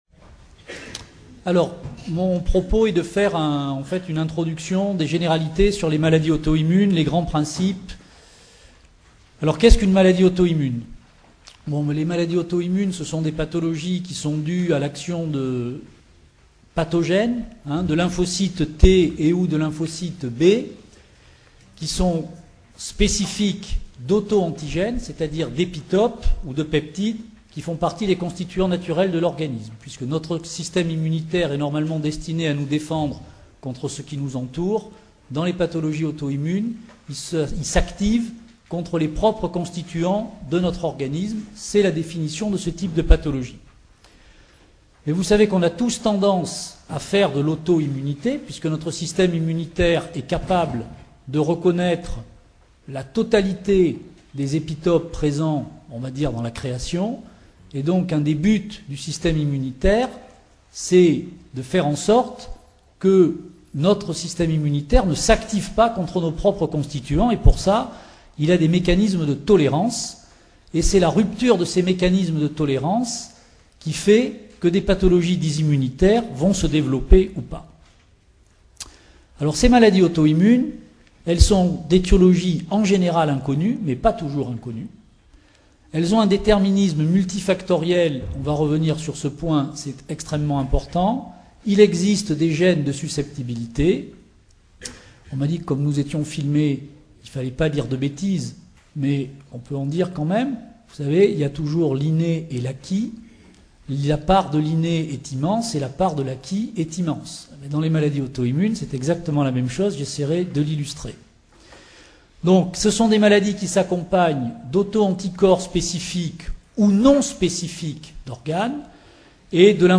Journées Victor Segalen - Université Bordeaux 2 Formation Médicale Permanente : La journée des Généralistes Organisée dans le cadre des Journées Victor Segalen 2010 par l’Unité Mixte de Formation Continue en Santé de l’Université Bordeaux 2, cette formation s’adresse avant tout aux médecins généralistes.